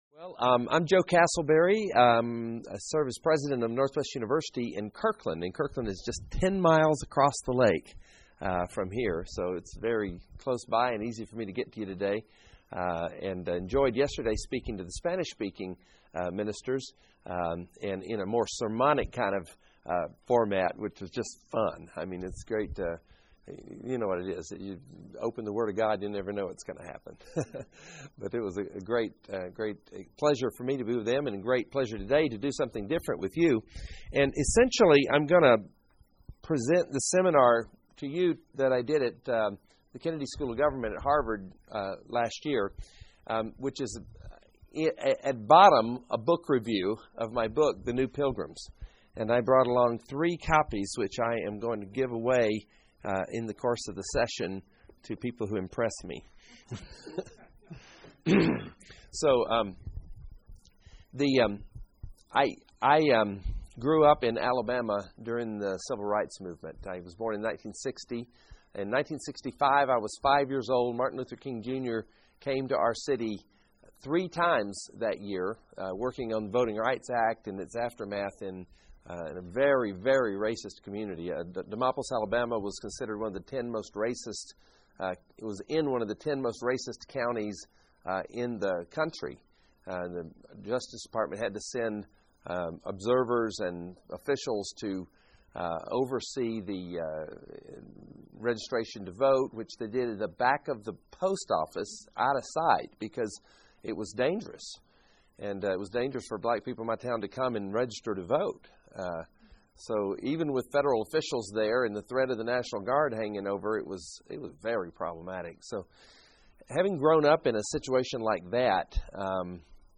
Workshop: The new pilgrims